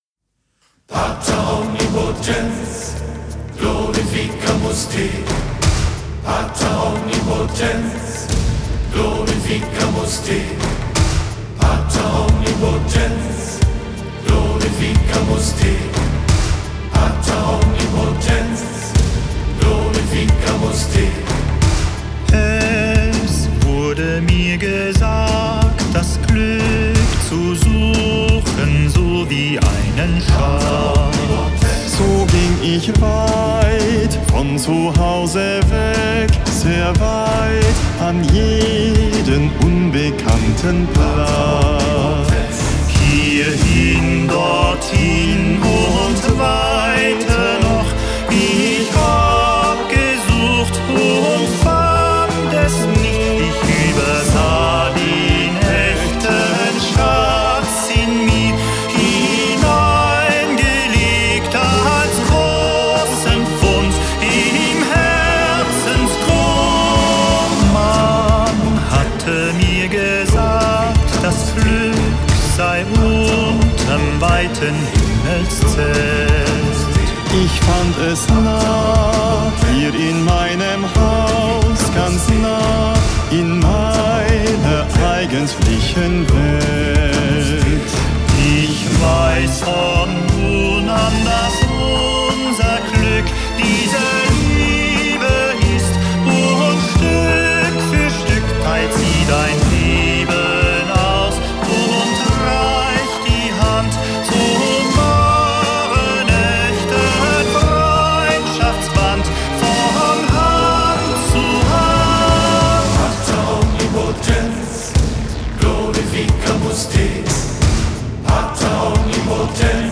Genre: Gregorian chant / Pop-Mystic / Choral
/ Neo-Classical / New Age